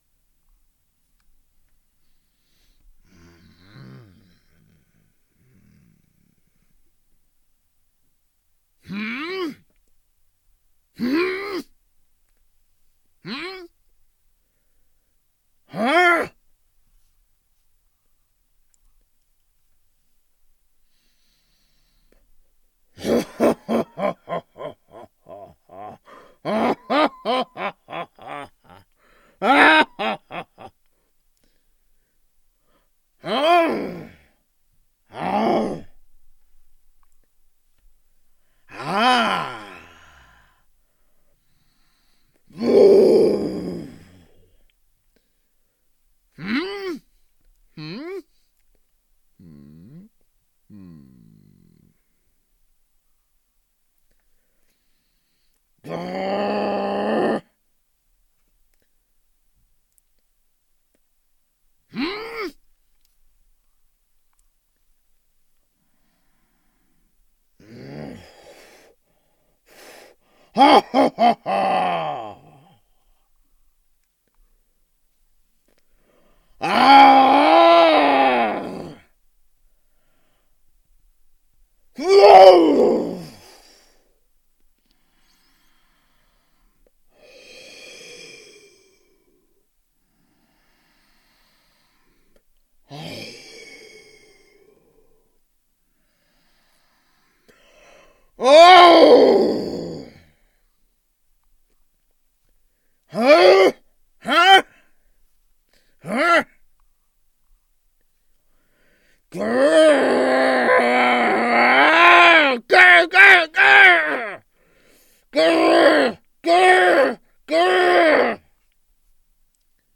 Male
Yng Adult (18-29), Adult (30-50)
I can perform as leading and supporting characters, with various tonal ranges, and making characters, and creatures, sound dynamically interesting for cartoons, anime, video games, and more!
Performed A Dragon For Client